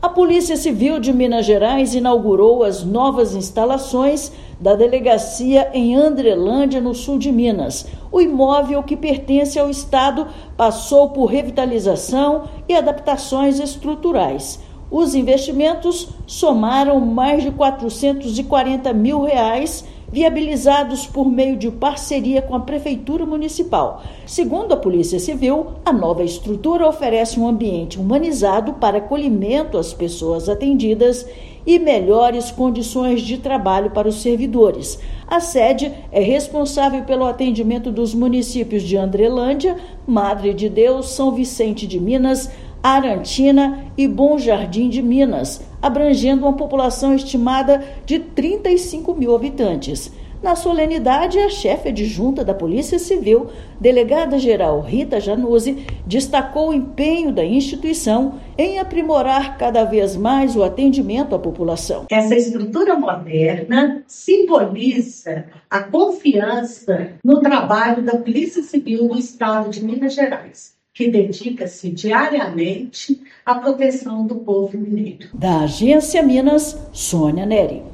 Localizada em Andrelândia, unidade conta com instalações modernas para melhorar o atendimento e aprimorar as condições de trabalho da equipe. Ouça matéria de rádio.